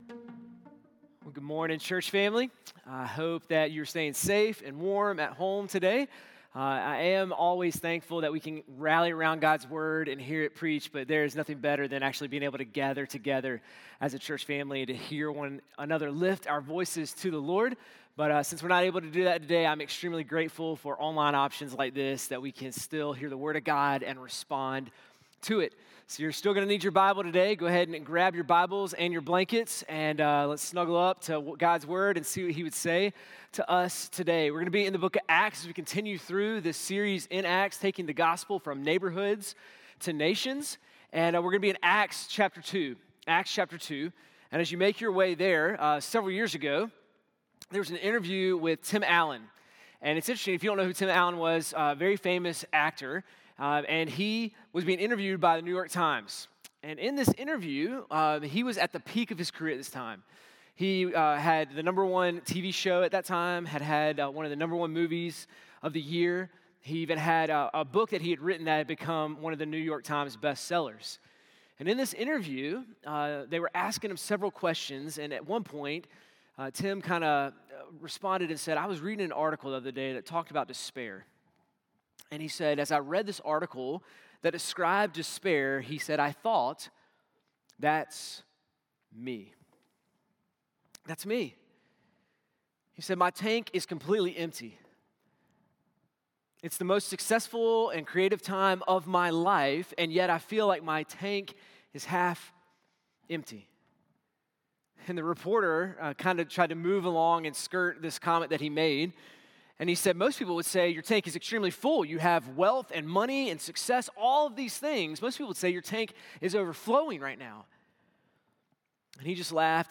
sermon-1-25-26.mp3